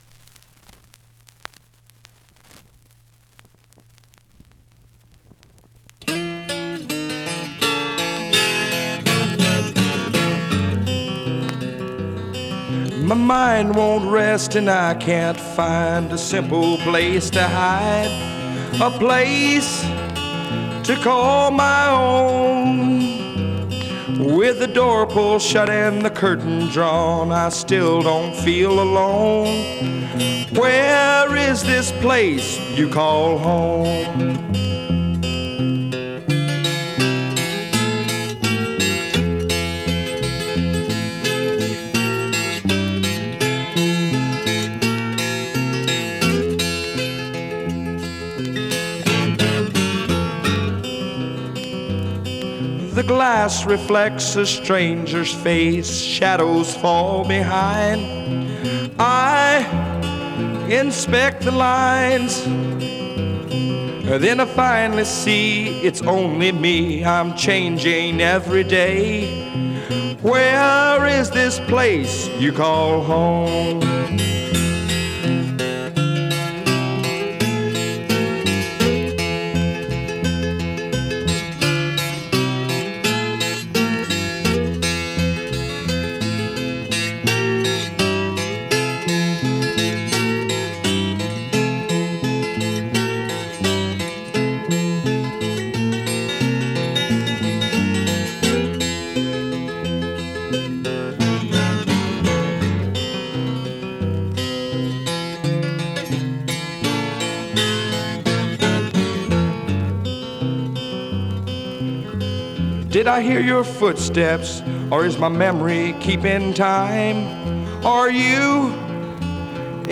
1970 Demo Album
the recording engineer categorized it as “baroque folk”.
twelve string
bass.  its the only take that we didnt over-dub embellishments on.
the first take was balanced on two tracks with my voice on half of each one and the instruments on either side.